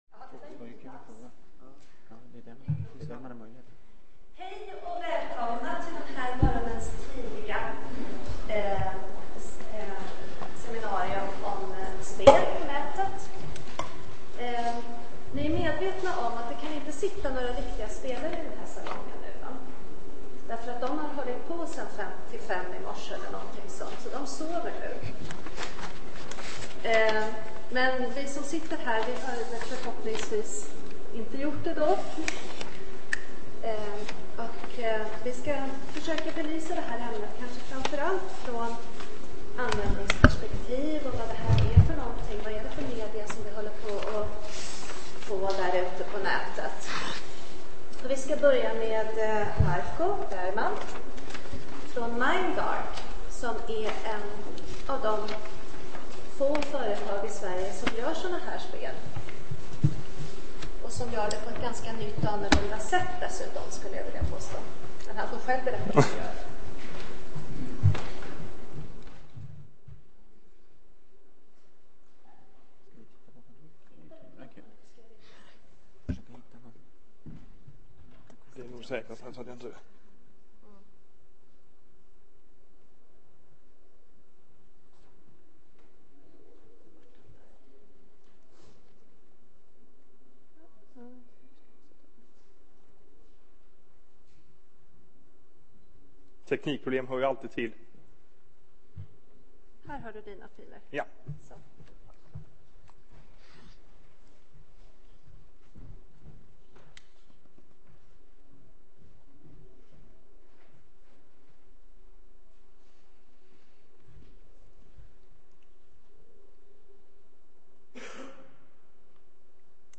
Samtidigt p�g�r en debatt om farorna med ett alltf�r intensivt spelande. Seminariet speglar b�da dessa fr�gor utifr�n unders�kningar som gjorts.